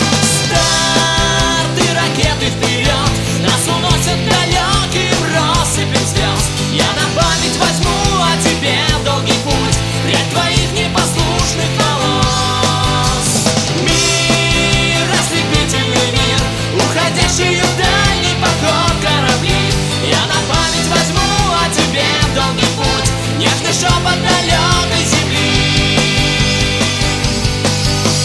музончик